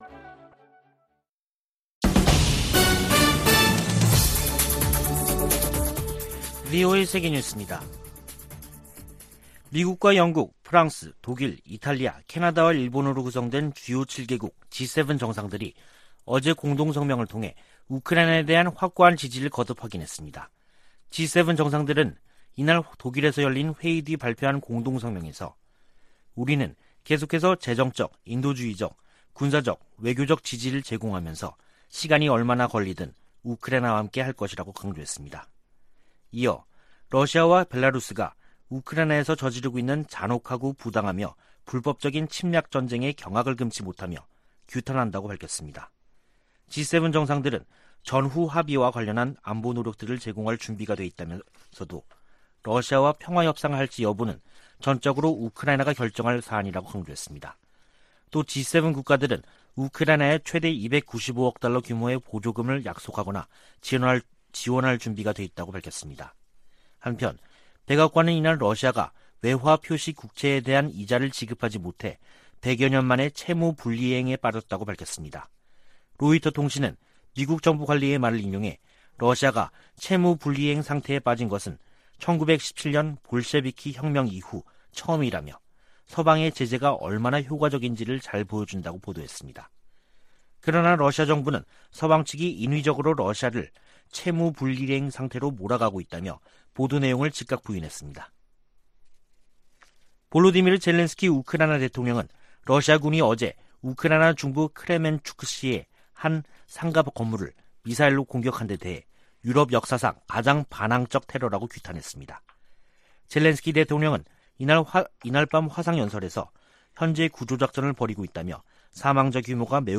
VOA 한국어 간판 뉴스 프로그램 '뉴스 투데이', 2022년 6월 28일 3부 방송입니다. 존 아퀼리노 미 인도태평양사령관이 미한 동맹을 역내 자유 수호의 핵심축이라고 말했습니다. 미 태평양함대사령관이 한일 국방장관 등을 만나 북한의 도발과 한반도 안보 상황을 논의했습니다. 유엔 산하 포괄적 핵실험금지 조약기구(CTBTO)가 북한에 핵실험 유예 약속 연장을 촉구했습니다.